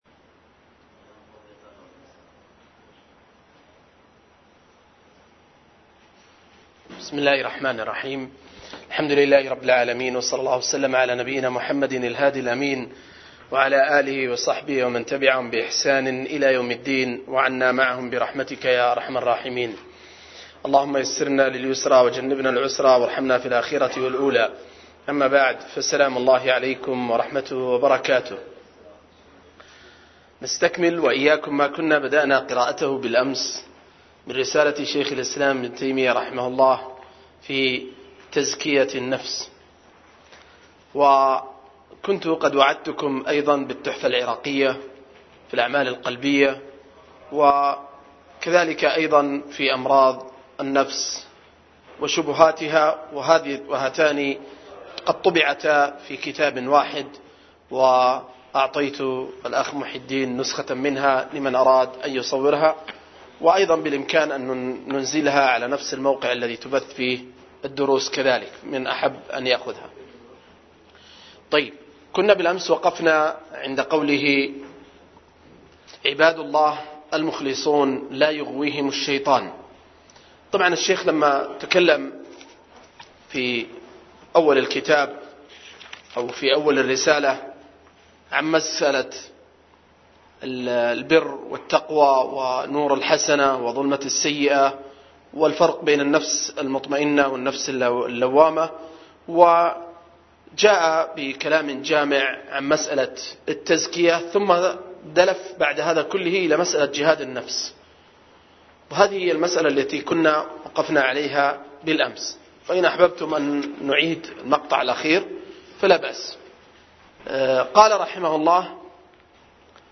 02- رسالة تزكية النفس لشيخ الإسلام – قراءة وتعليق – المجلس الثاني